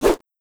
SFX_falloEspada3.wav